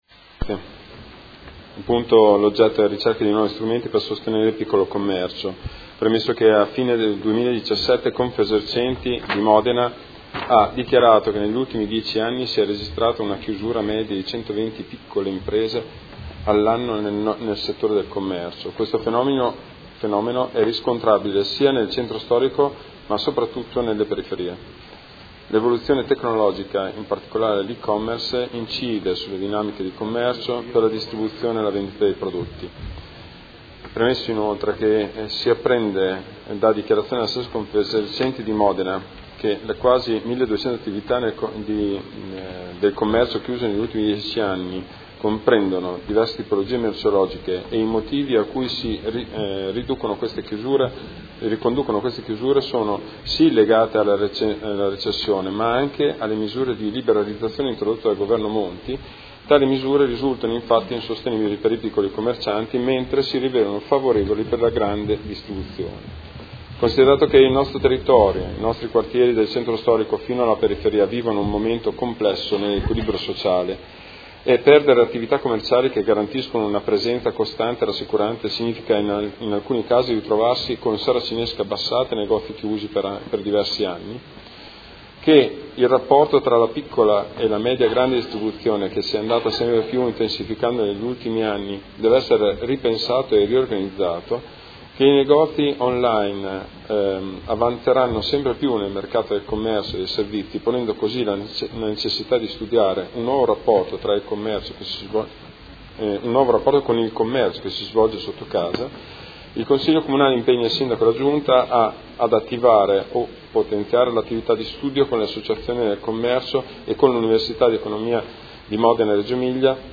Seduta del 22/02/2018 Presenta ordine del giorno nr. 25826 - Ricerca di nuovi strumenti per il sostenere il piccolo commercio